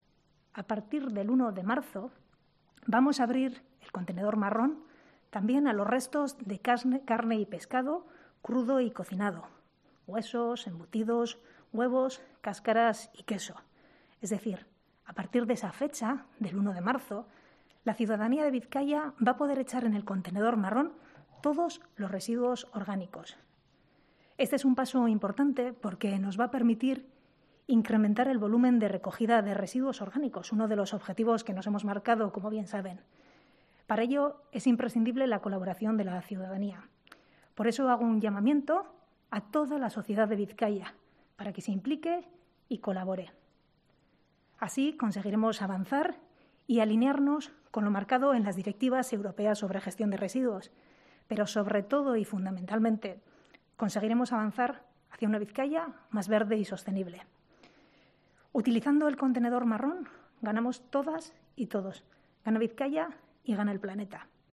Elena Unzueta, diputada foral de Sostenibilidad y Medio Natural